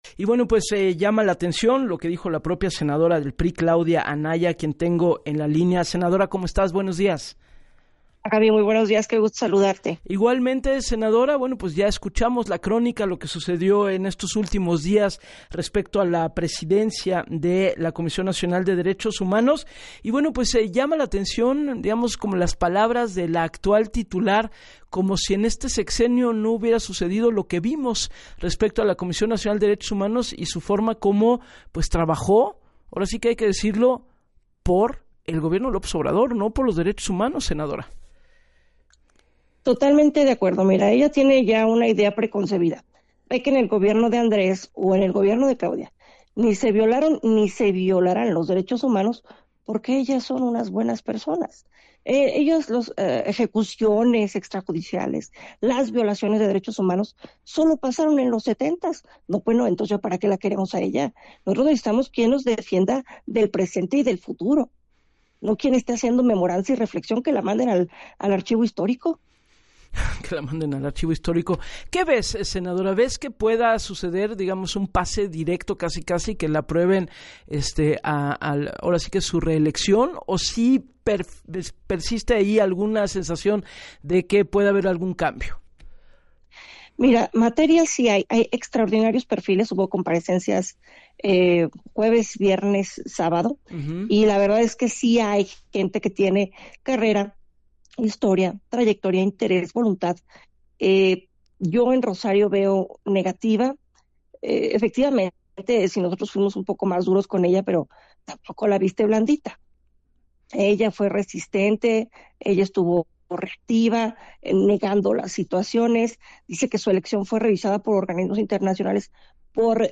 La priista, reconoció en el espacio de “Así las Cosas” con Gabriela Warkentin, que entre los 45 aspirantes “hay extraordinarios perfiles… y con disposición, en Rosario, veo negativa”.